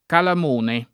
[ kalam 1 ne ]